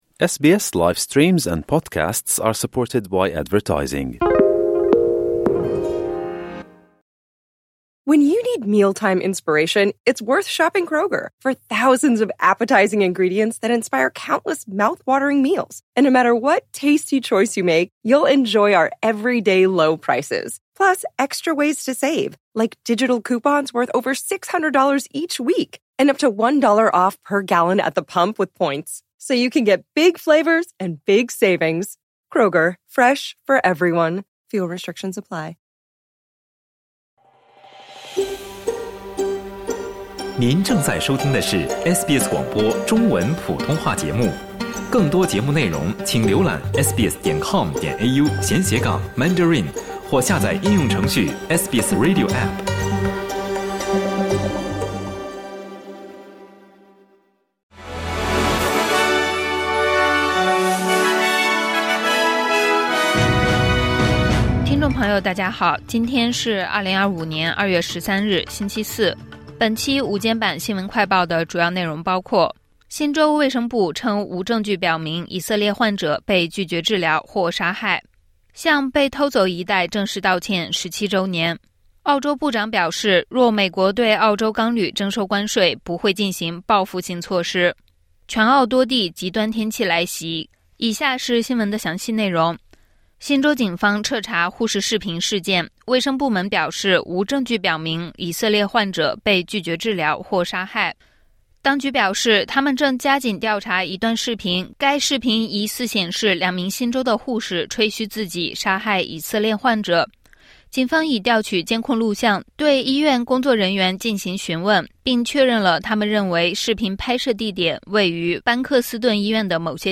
【SBS新闻快报】新州护士视频后续：当局否认以色列患者被拒治疗或杀害